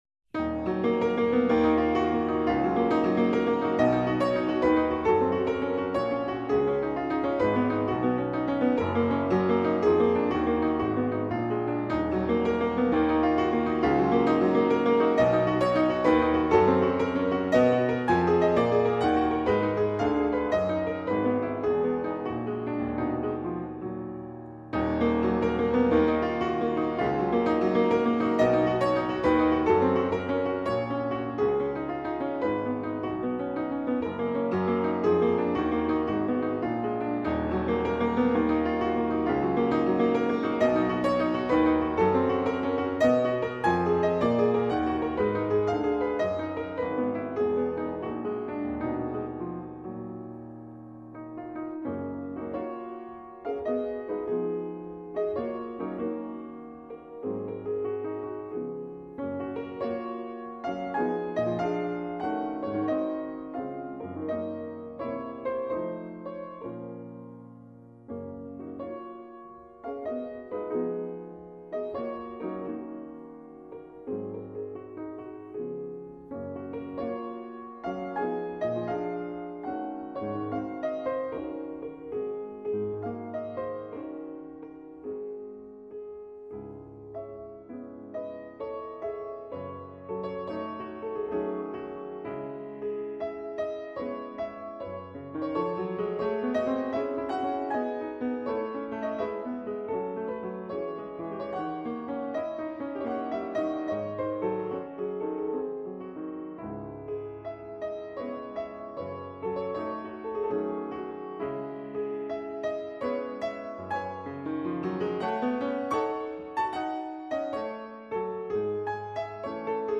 Kūriniai fortepijonui / Piano Works
fortepijonas / piano